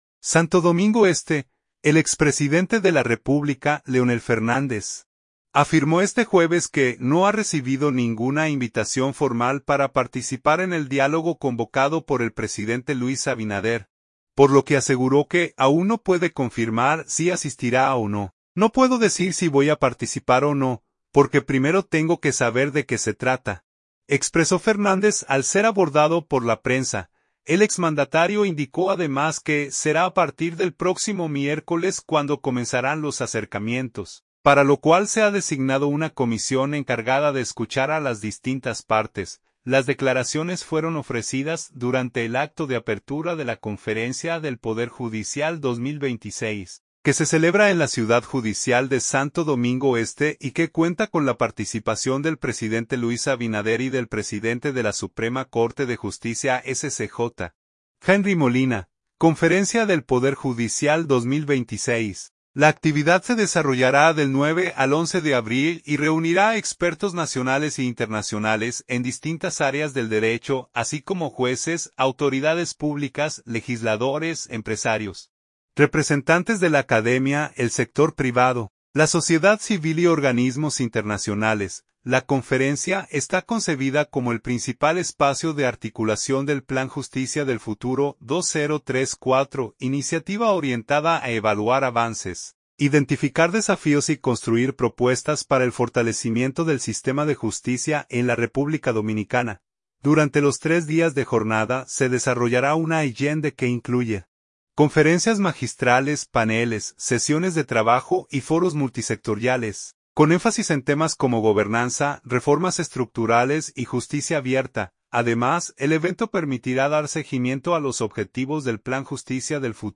“No puedo decir si voy a participar o no, porque primero tengo que saber de qué se trata”, expresó Fernández al ser abordado por la prensa.
Las declaraciones fueron ofrecidas durante el acto de apertura de la Conferencia del Poder Judicial 2026, que se celebra en la Ciudad Judicial de Santo Domingo Este y que cuenta con la participación del presidente Luis Abinader y del presidente de la Suprema Corte de Justicia (SCJ), Henry Molina.